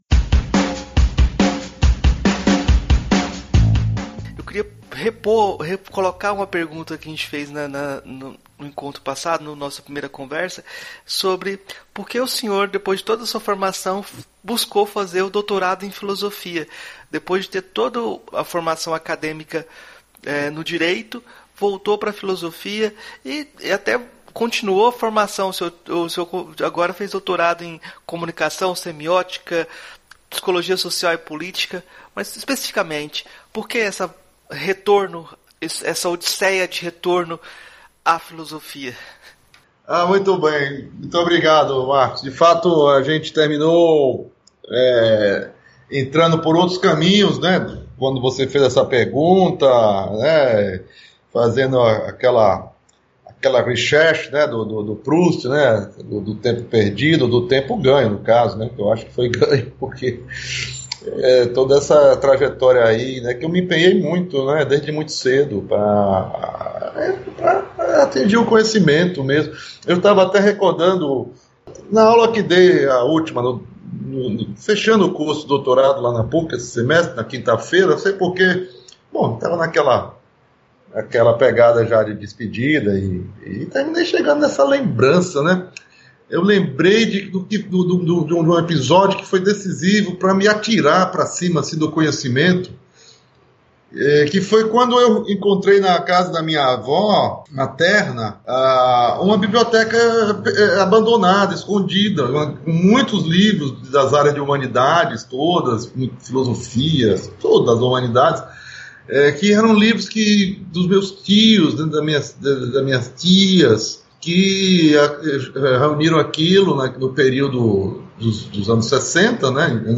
Esse diálogo é a segunda parte da conversa do episódio 95.